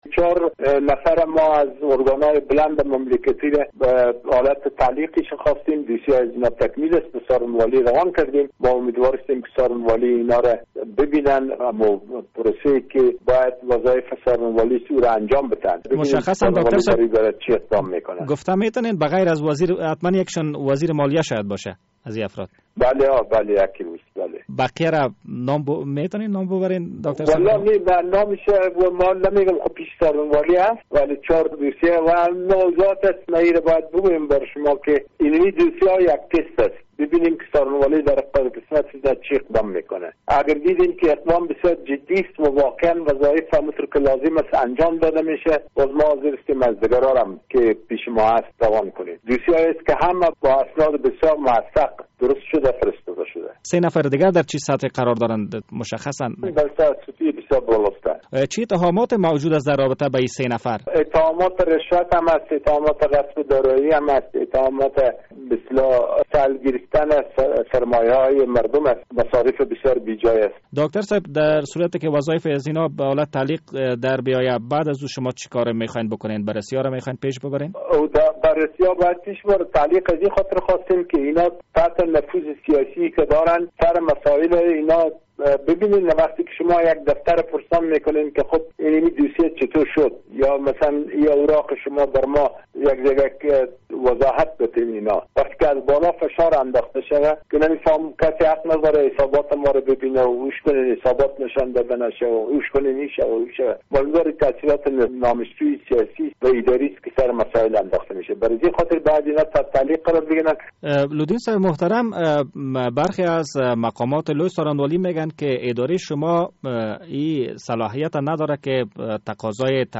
ادارهء عالی مبارزه با فساد اداری افغانستان یک بار دیگر از لوی څارنوالی می خواهد مطابق به خواست این اداره وظایف چهار مقام حکومتی را به حالت تعلیق در آورد. داکتر عزیز الله لودین رییس این اداره به روز جمعه در مصاحبه با رادیو آزادی گفت...